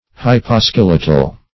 Search Result for " hyposkeletal" : The Collaborative International Dictionary of English v.0.48: Hyposkeletal \Hy`po*skel"e*tal\, a. [Pref. hypo- + skeletal.]
hyposkeletal.mp3